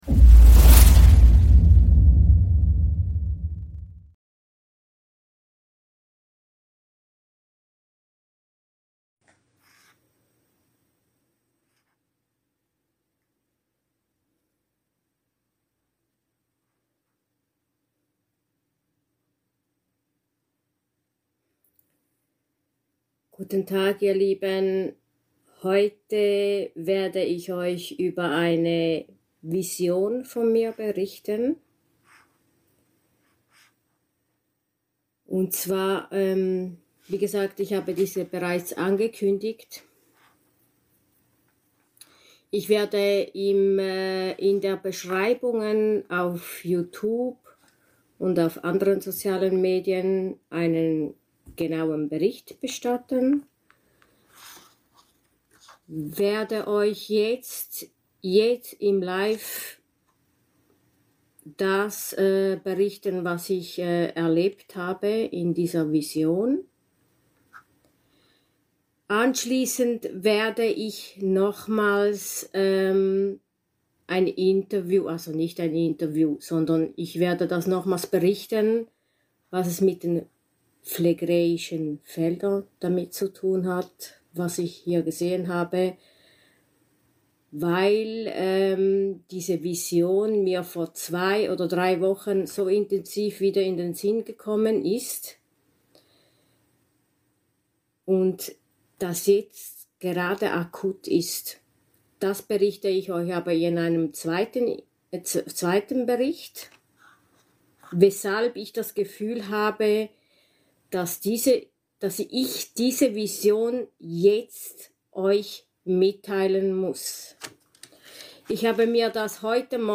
Spontan. Tatsächliche Vision ab 0,3 Diese Vision hatte ich ca. vor zwei Jahren und es ist seit zwei Wochen oder drei wieder so präsent geworden und eine innere Stimme sagt mir du musst darüber berichten, getrau dich einfach, egal was andere denken, viele werden das Verstehen und Wissen und andere stehen erst am Beginn Ihres Erwachens.